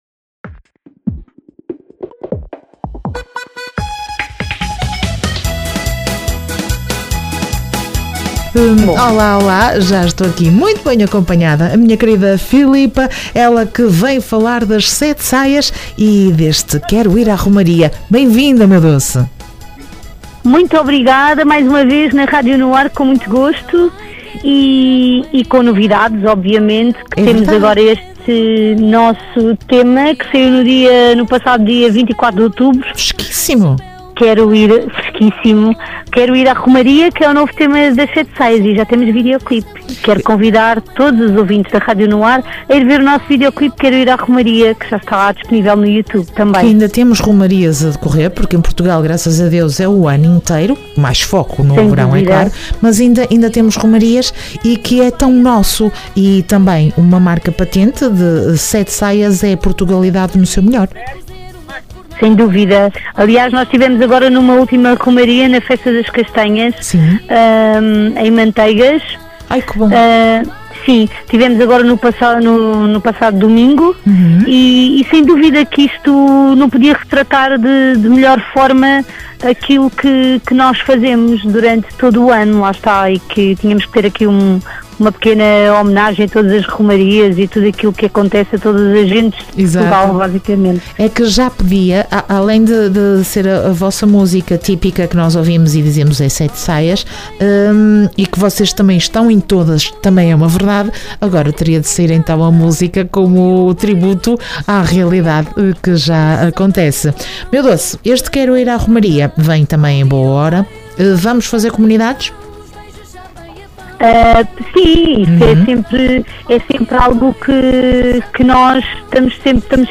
Entrevista 7 Saias dia 18 de Novembro.